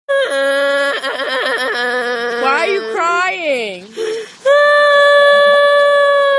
Best Crying
Play Best Crying Sound Button For Your Meme Soundboard!